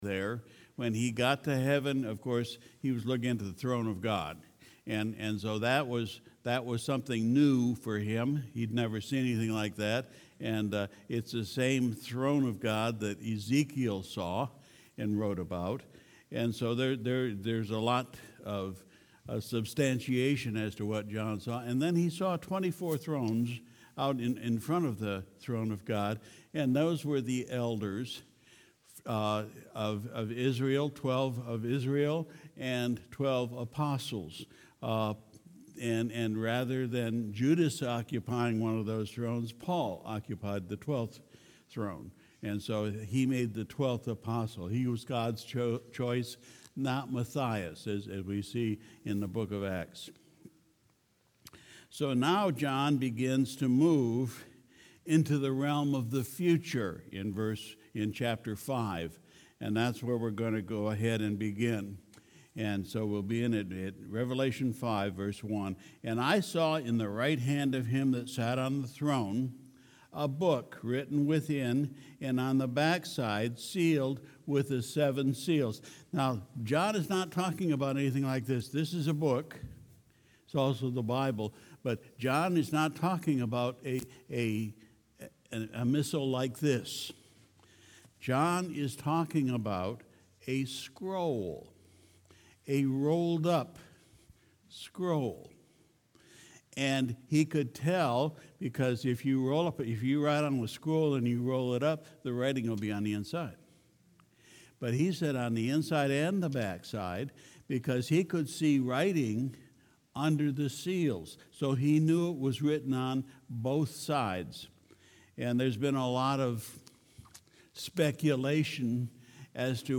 November 24, 2019 Sunday Evening Service We continued our study in Revelation